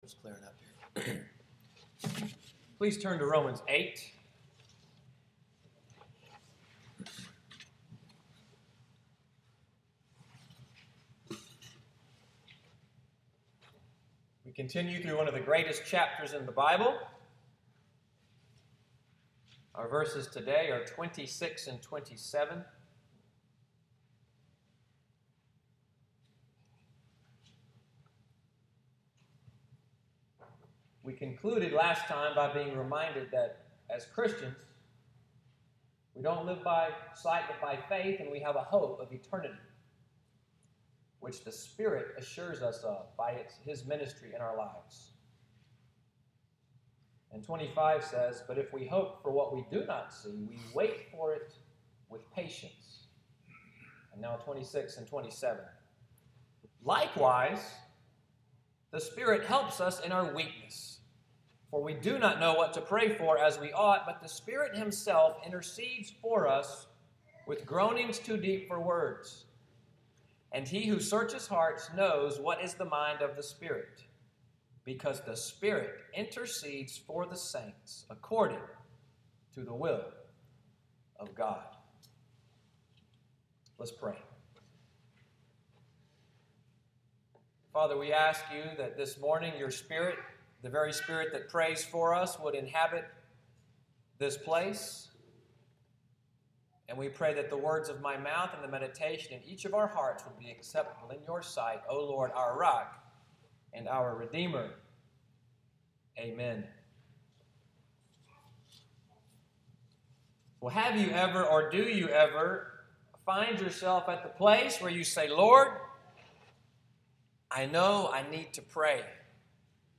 Sunday’s sermon, “The Help of the Spirit,” August 9, 2015